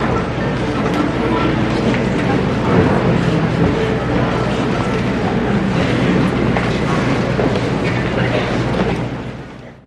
Auditorium | Sneak On The Lot
Orchestral Audience General Ambience, Some Violin